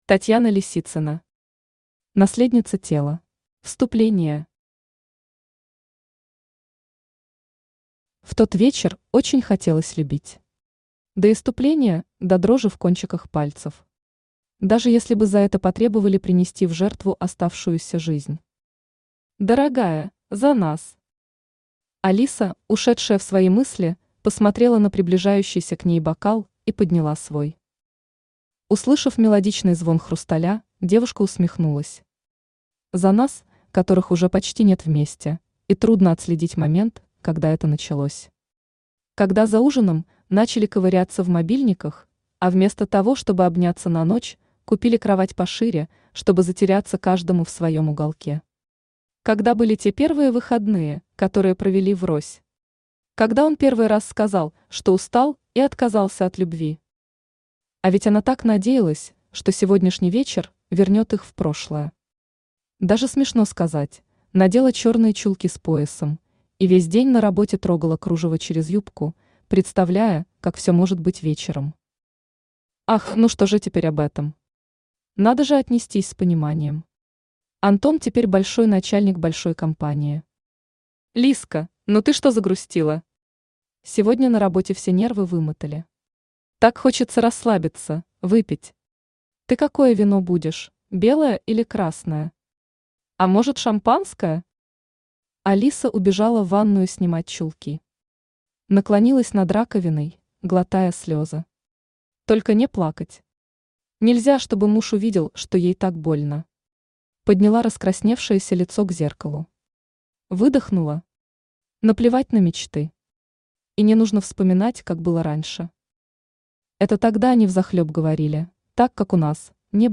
Аудиокнига Наследница тела | Библиотека аудиокниг
Aудиокнига Наследница тела Автор Татьяна Лисицына Читает аудиокнигу Авточтец ЛитРес.